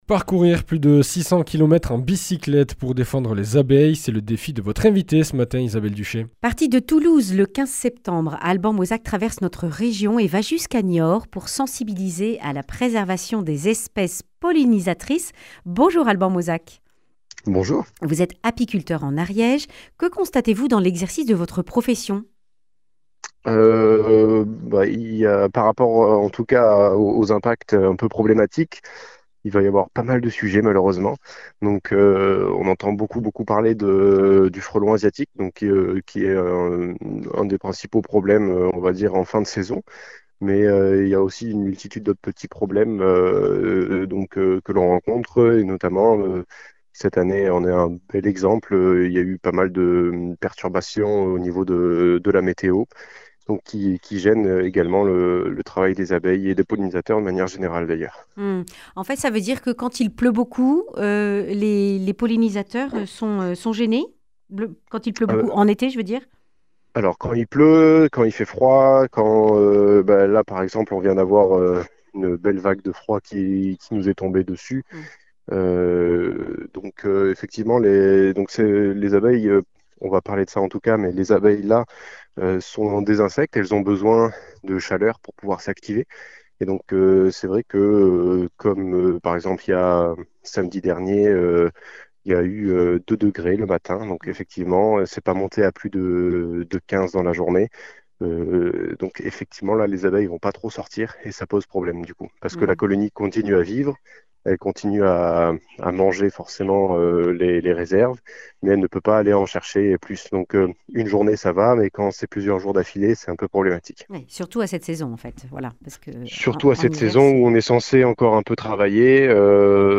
Accueil \ Emissions \ Information \ Régionale \ Le grand entretien \ 600 km à bicyclette pour sensibiliser sur la préservation des pollinisateurs.